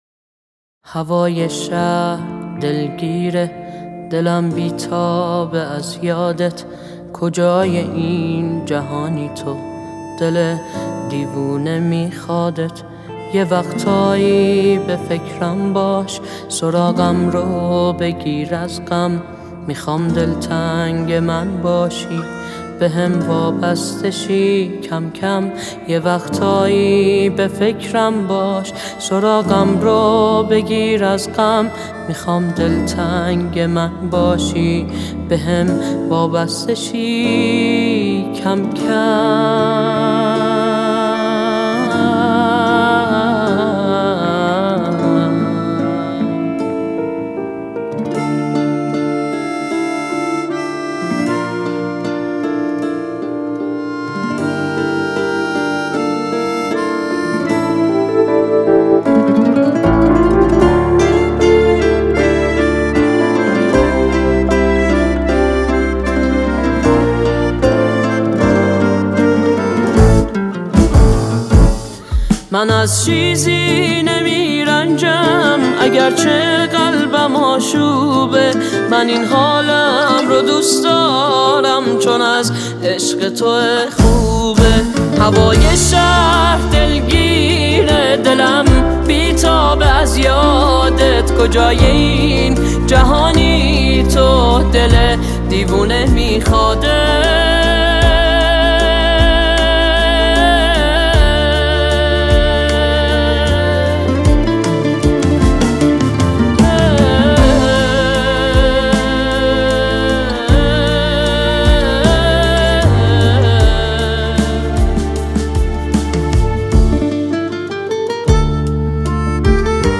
پاپ
اهنگ ایرانی